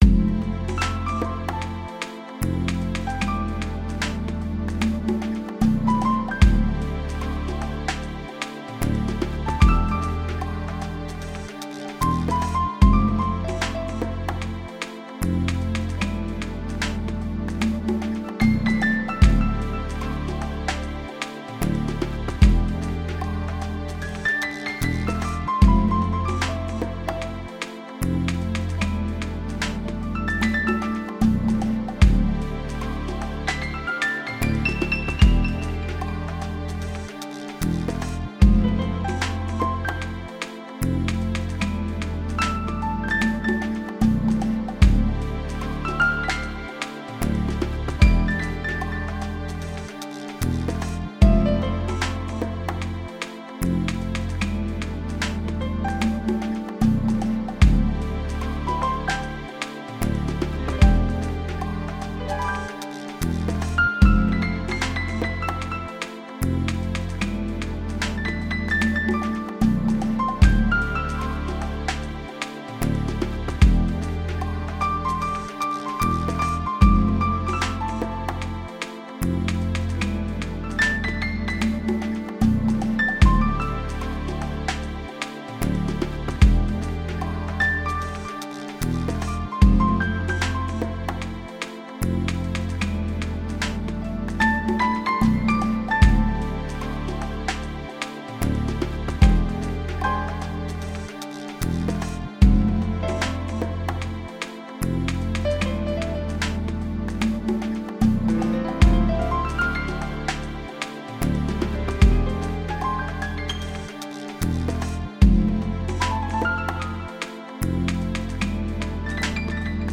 Web_Loops_(Piano_Solo)_75.mp3